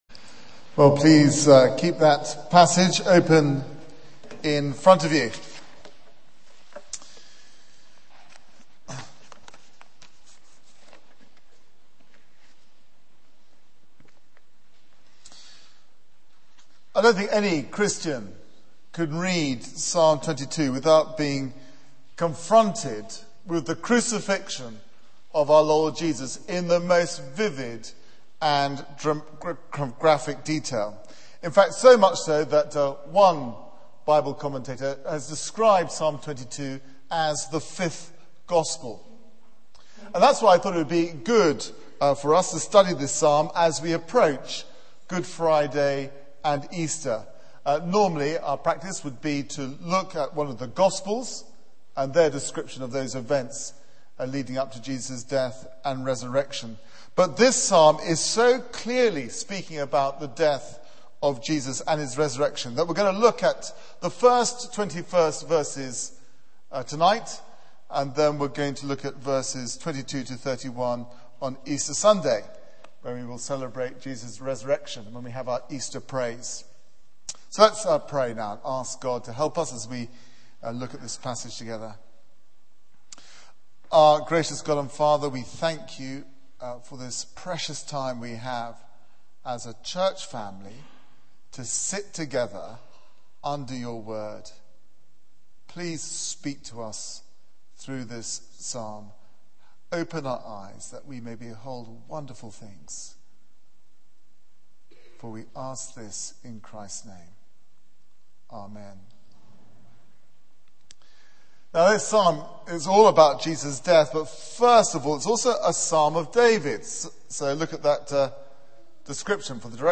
Media for 6:30pm Service on Sun 28th Mar 2010 18:30 Speaker
Passage: Psalm 22:1-21 Series: Easter Psalms Theme: Christ Forsaken Sermon